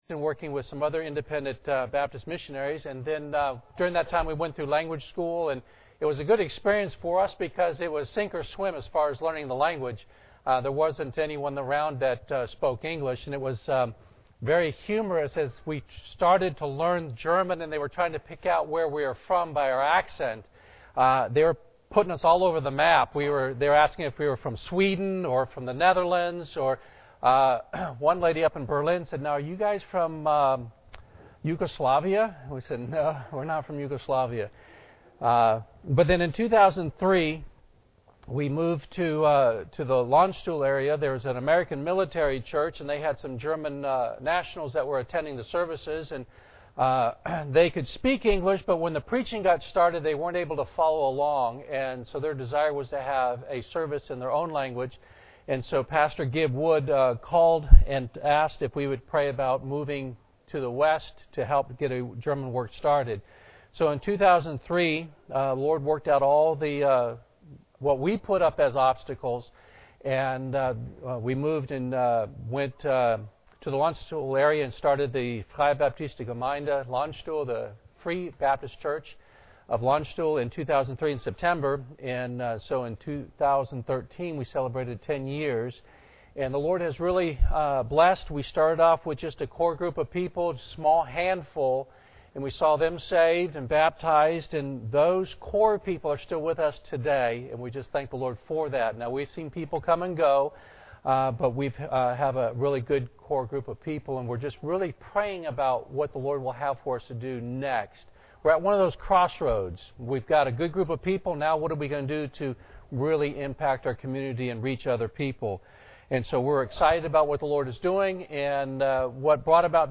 Service Type: Wednesday Evening